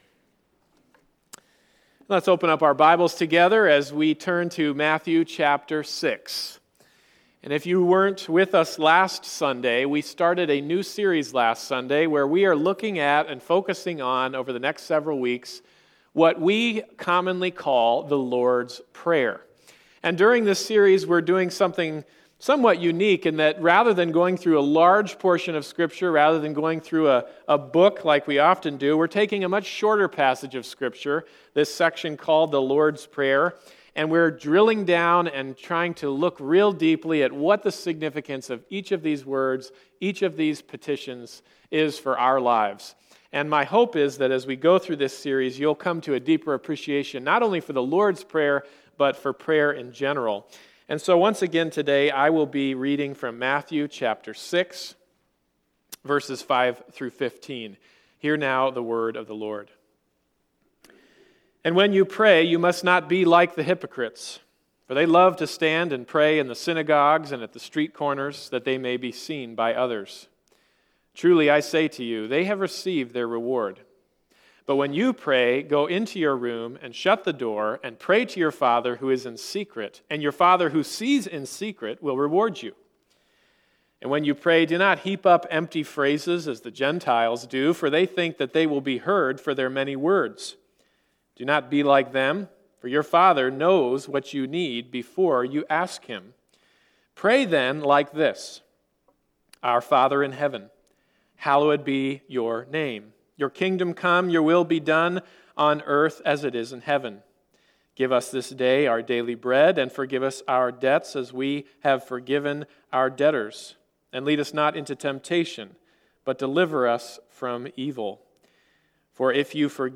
Bible Text: Matthew 6:5-15 | Preacher